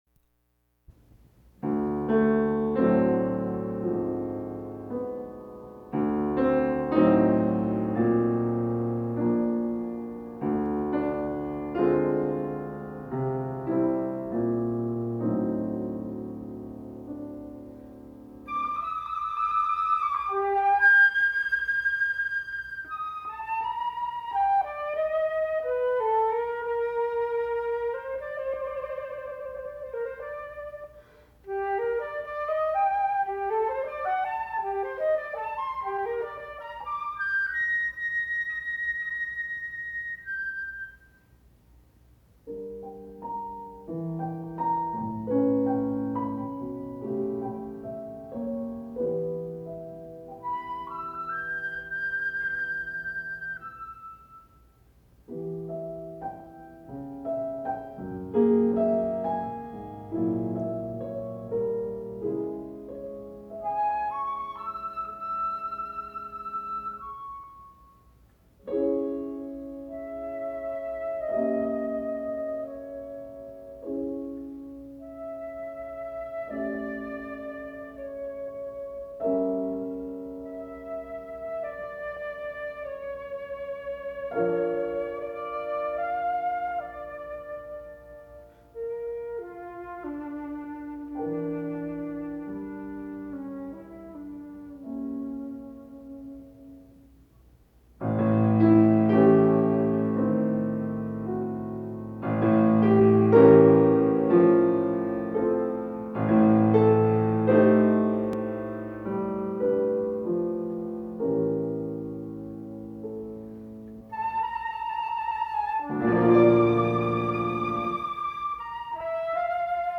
سوناتا الكمان